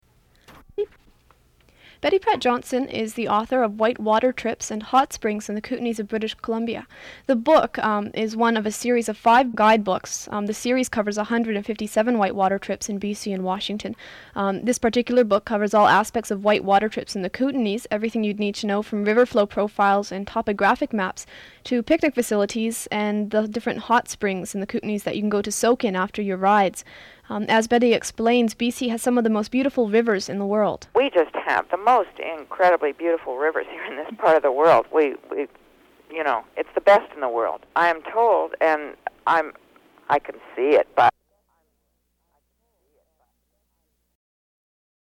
Recording of a short excerpt from an interview